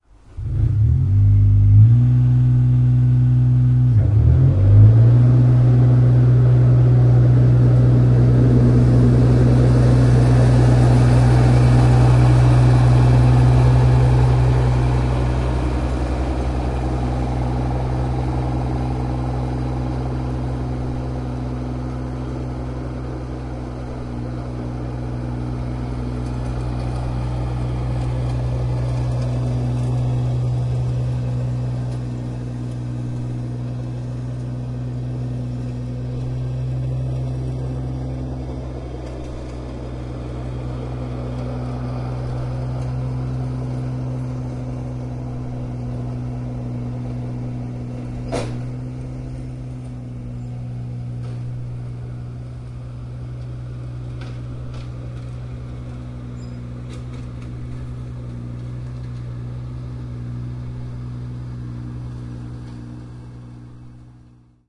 下水道
描述：有水滴入的下水道。 用MAudio 24/96录制的。
Tag: 现场录音 下水道 飞溅 湿